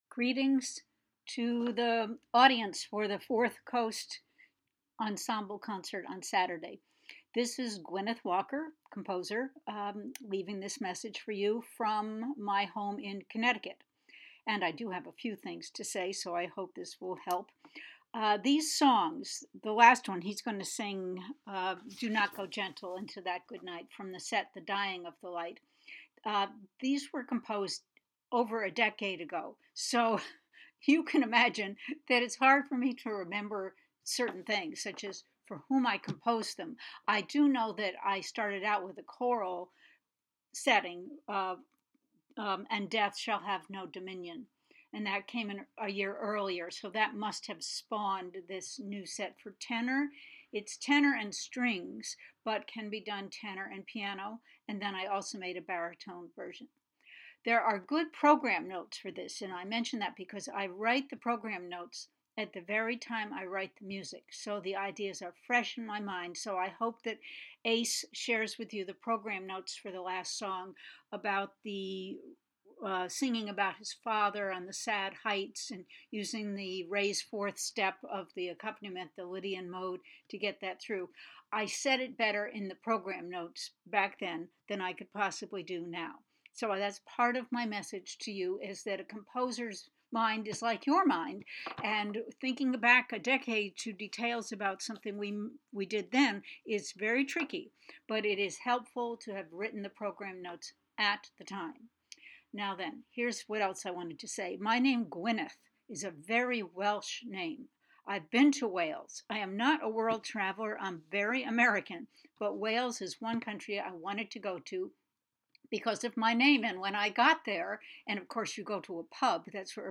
MP3 file with some comments about this work from the composer recorded for a 2021 concert.